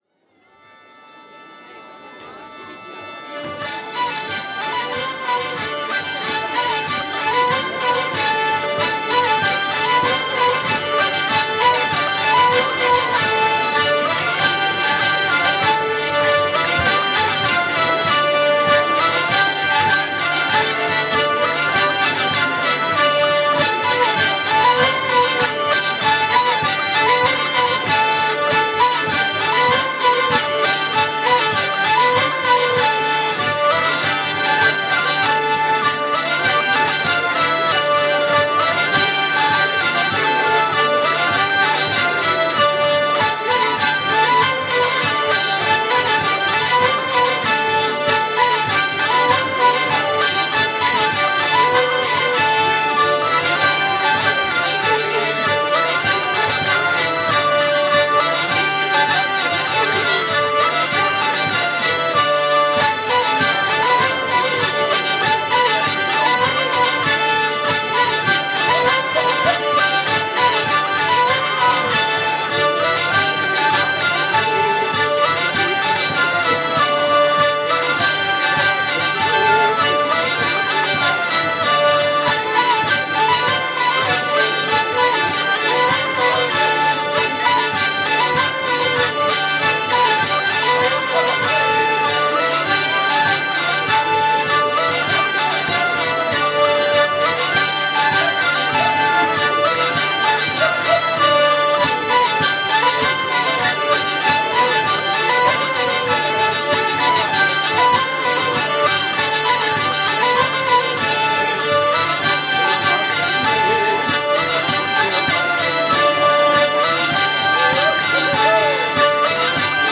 :mp3:2013:soiree_stagiaires
16_tour-vielles.mp3